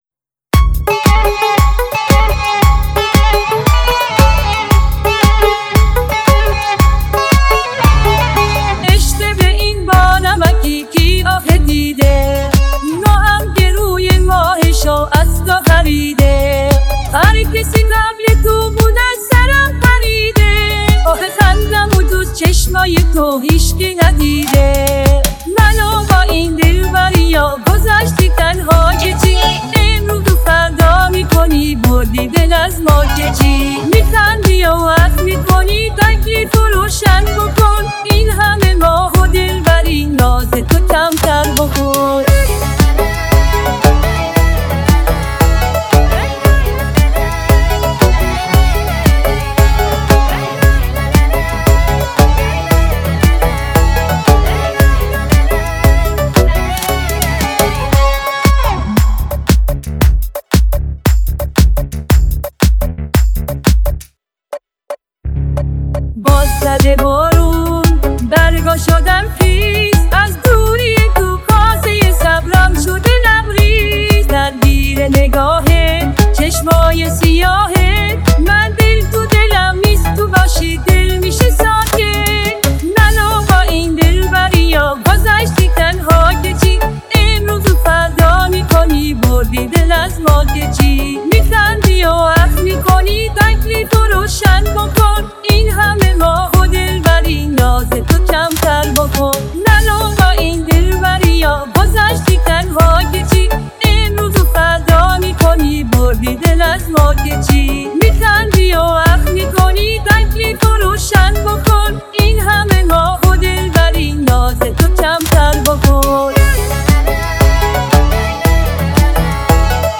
AI